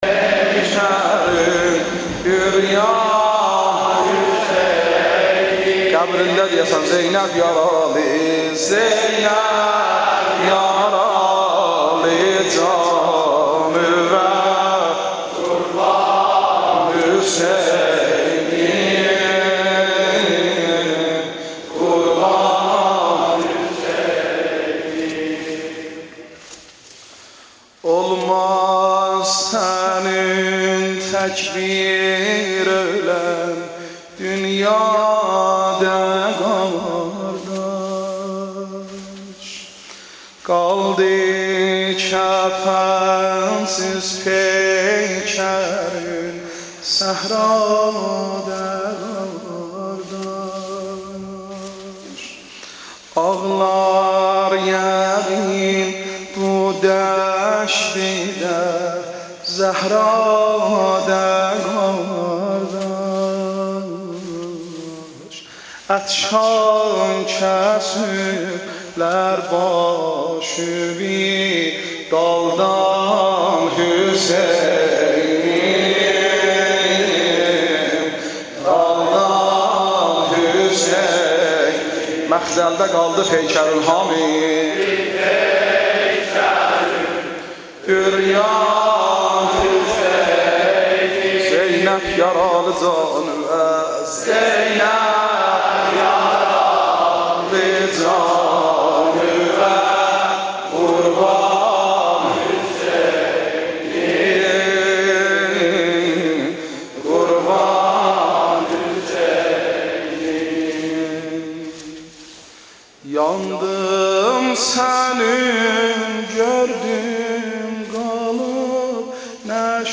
مقتل ده قالدی پیکرون عریان حسینیم زینب یارالی جانووه قربان حسینیم ،کلیپ، سخنرانی، مداحی، قرآن، نماهنگ، انیمشین، آموزش مداحی، آموزش قرآن،
مداحی آذری نوحه ترکی